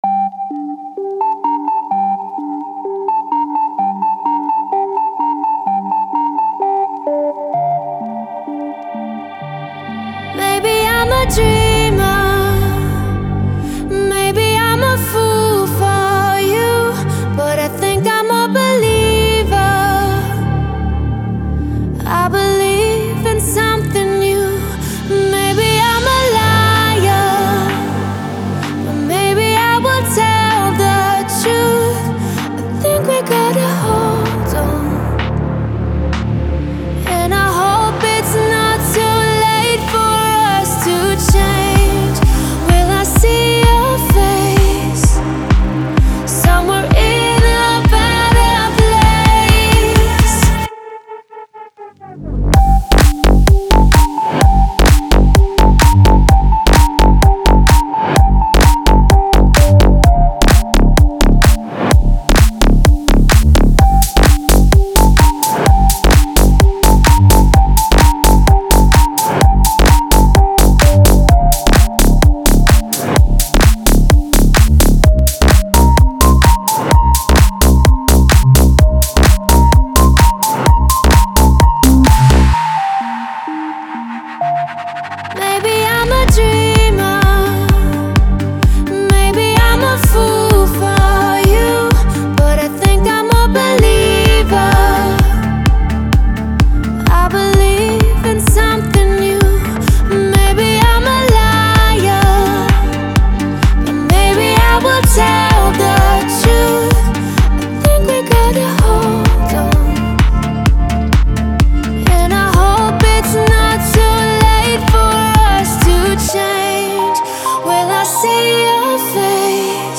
это энергичная электронная композиция